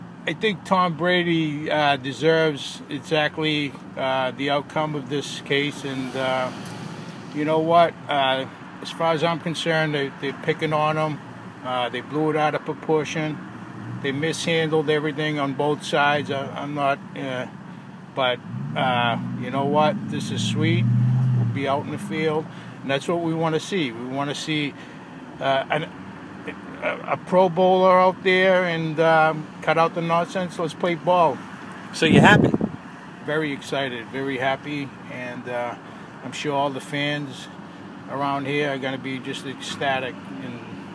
MAN GETTING OUT OF HIS CAR THURSDAY MORNING SAYS THE FANS WILL BE ECSTATIC.